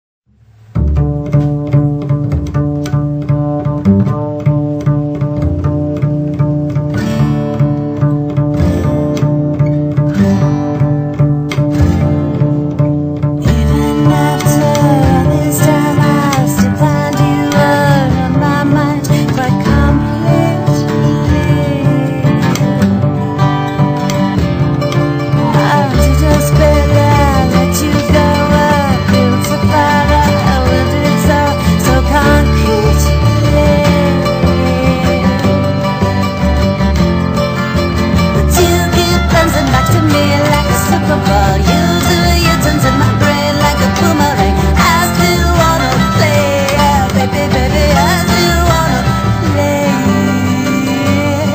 voice, violin, viola, mandolin, percussion and what not.
voice, 6 and 12 string guitars, trombone, snare drum